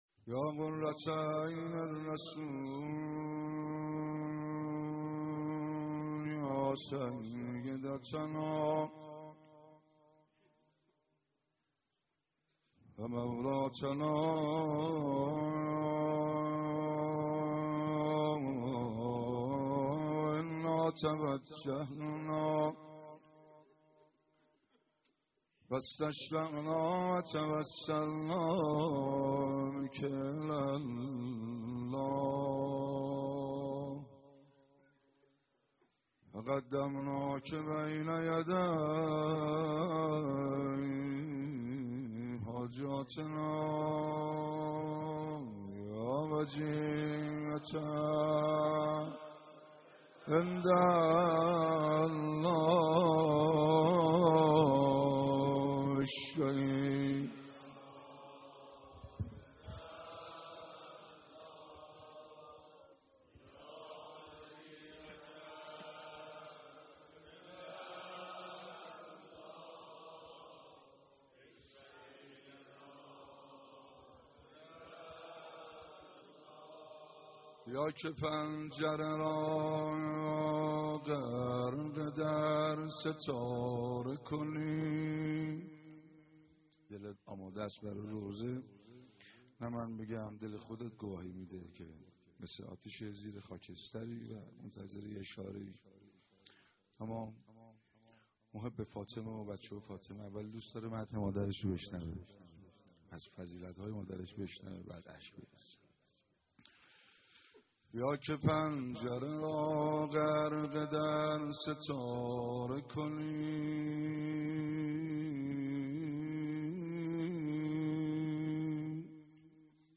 مداحی فاطمیه